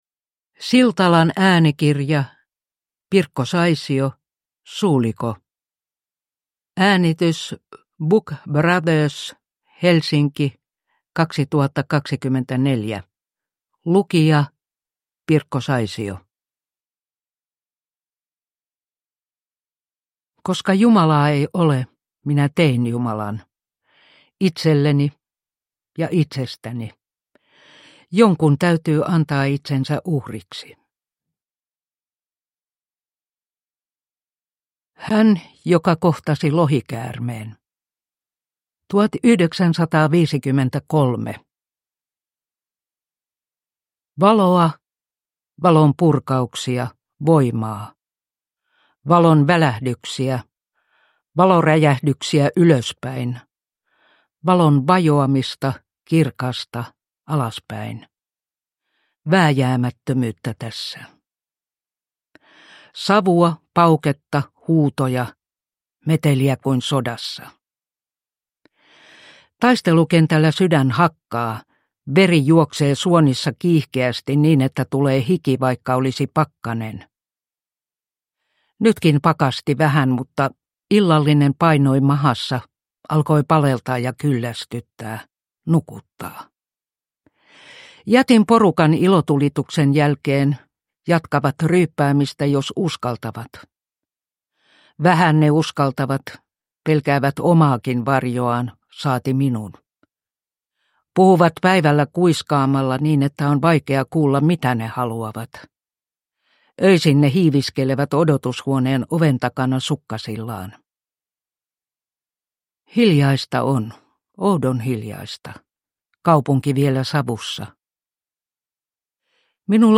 Suliko – Ljudbok
Uppläsare: Pirkko Saisio